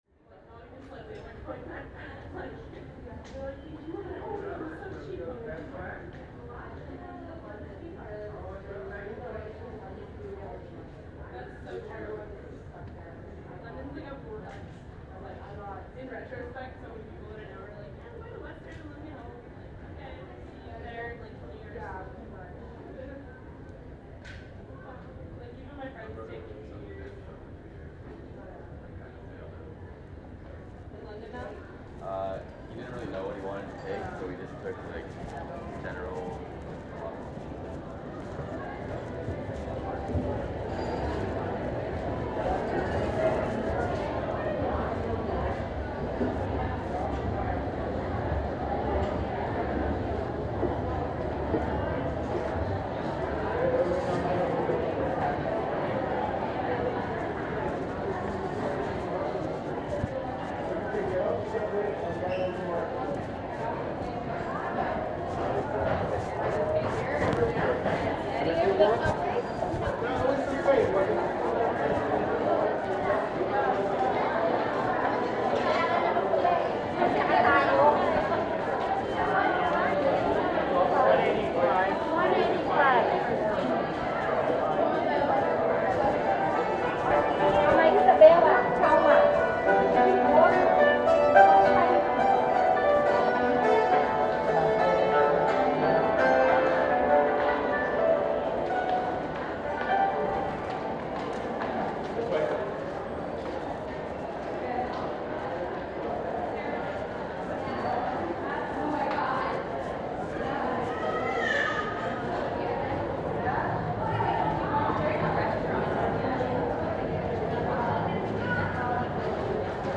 Union train station (Toronto)
A walk through the underground concourse at Union Station. Midway through the recording there is a Chinese stringed instrument busker.